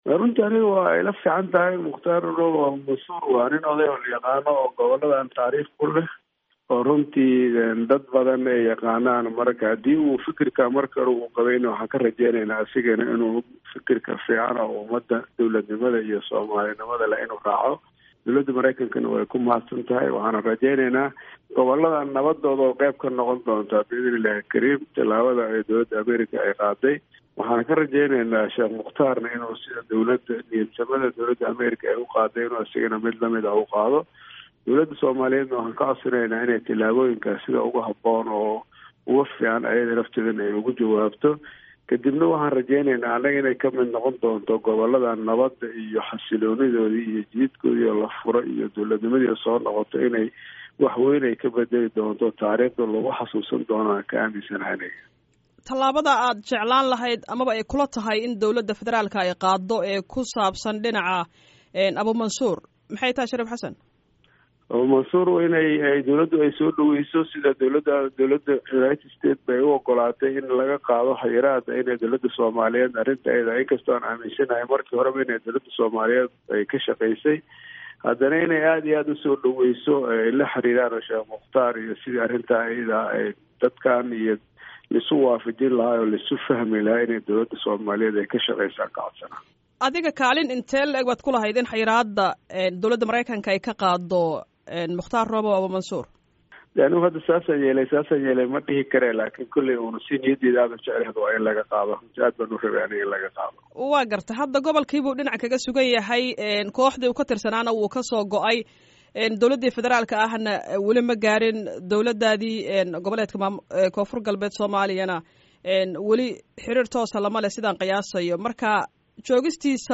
Wareysi: Shariif Xasan Sheekh Aadan